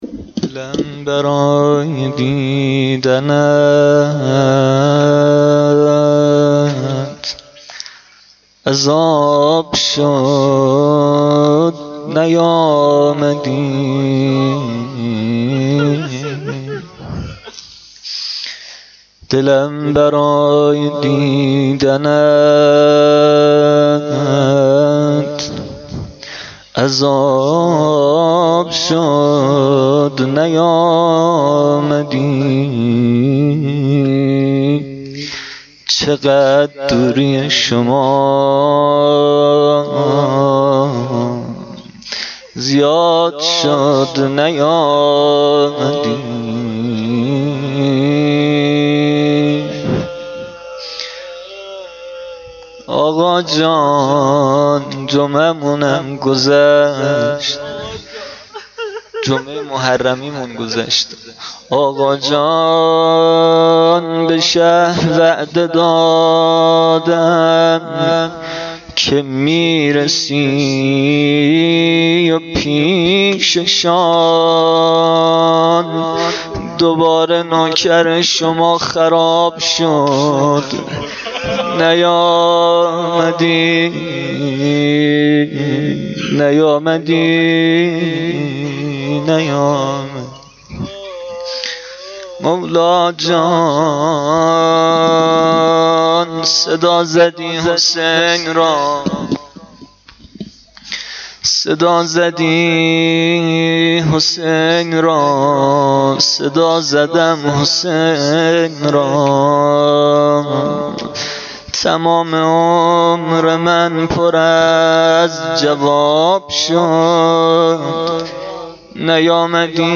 روضه شب هشتم محرم 93